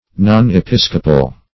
non-episcopal - definition of non-episcopal - synonyms, pronunciation, spelling from Free Dictionary
non-episcopal.mp3